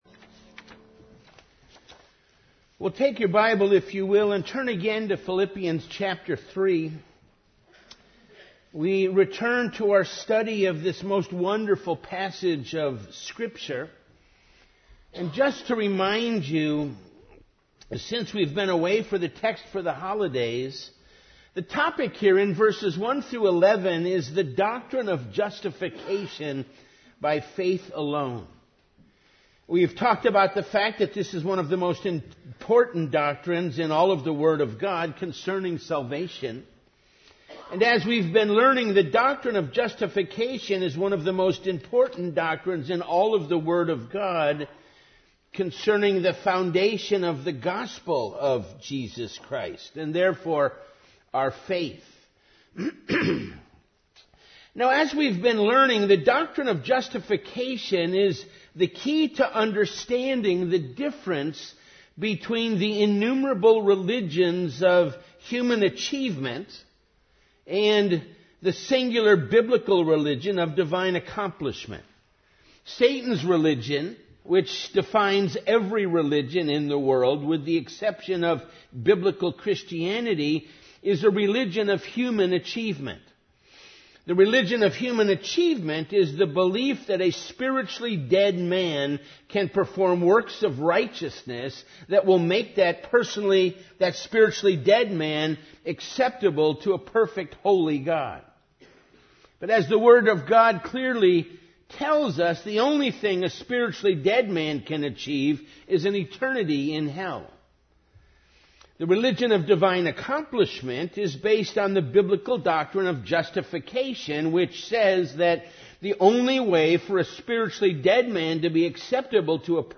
Evening Worship